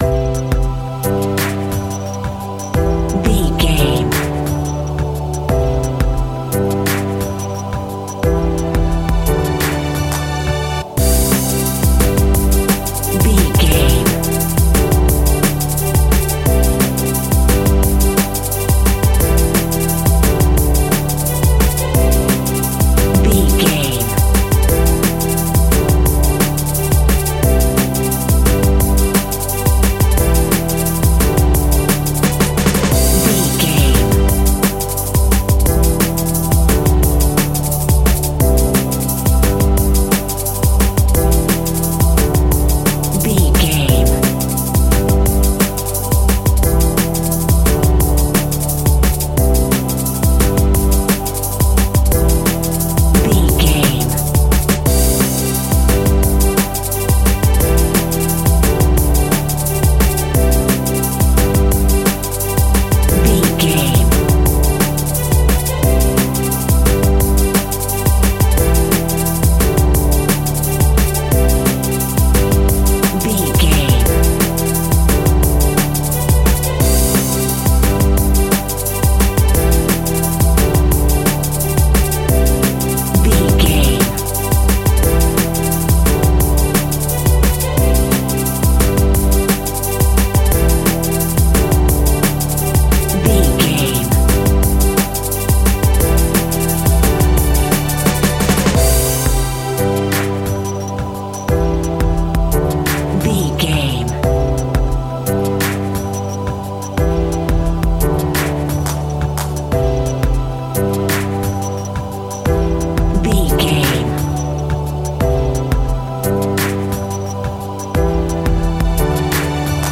Ionian/Major
groovy
uplifting
bouncy
futuristic
drums
synthesiser
electronic
sub bass
synth leads